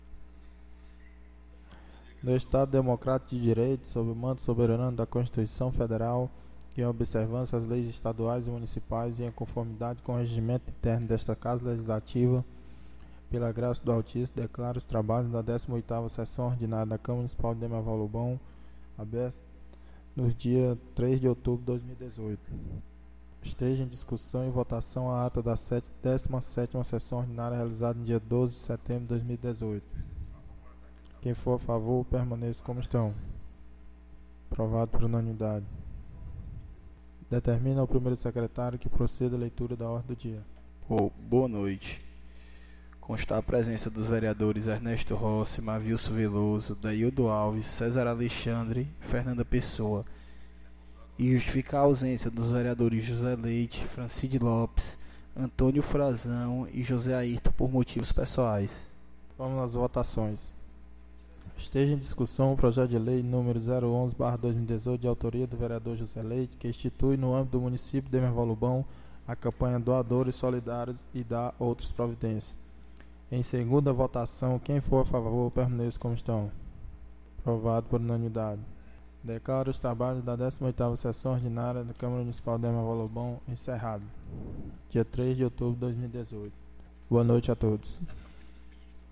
18ª Sessão Ordinária 03/10/2018